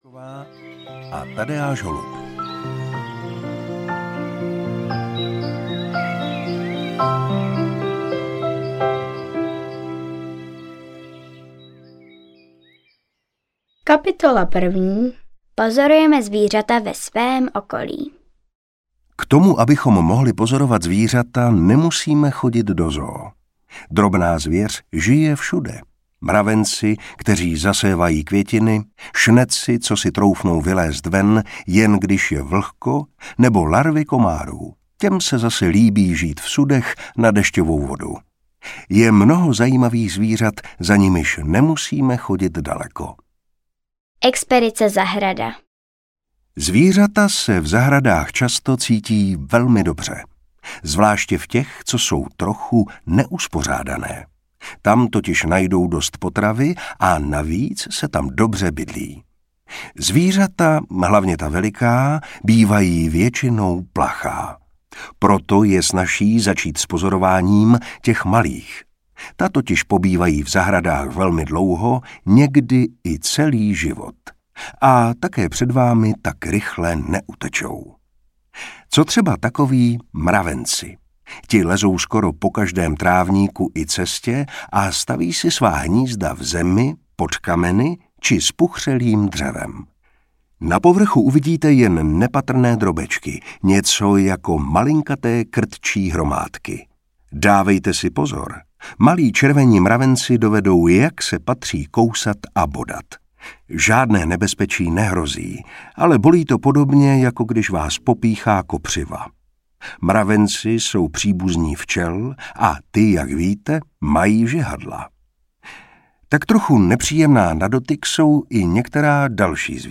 Víš, kde bydlí zvířata? audiokniha
Ukázka z knihy
• InterpretMartin Preiss